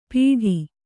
♪ pīḍhi